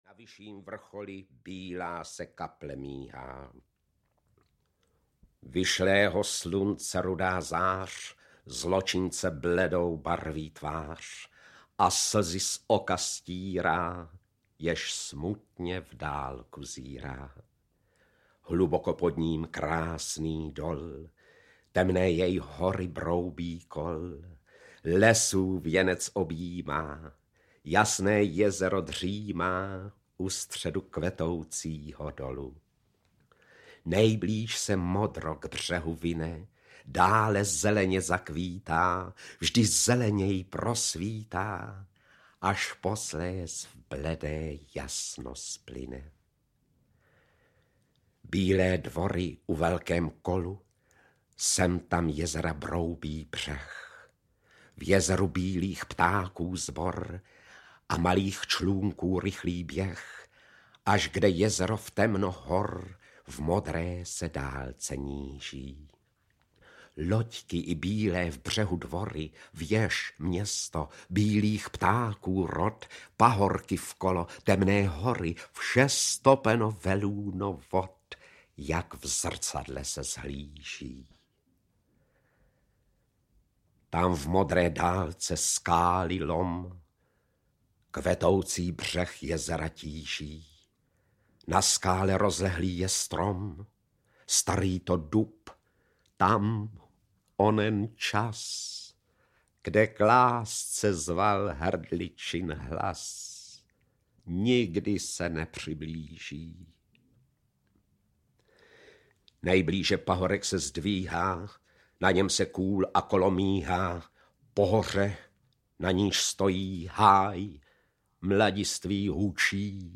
Ukázky z bohaté tvorby herce VLADIMÍRA ŠMERALA.
Audio kniha